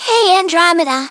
synthetic-wakewords
ovos-tts-plugin-deepponies_Nanako Dojima_en.wav